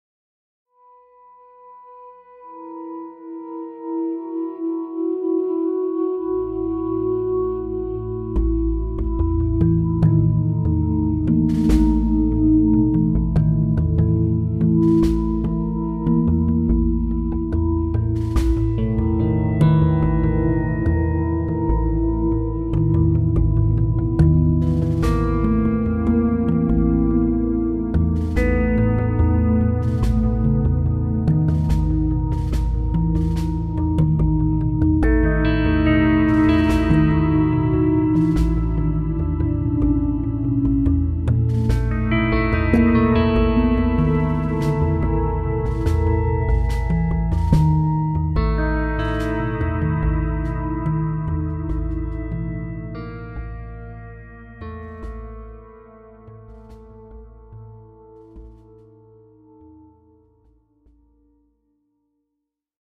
【中秋話劇背景音樂．1區】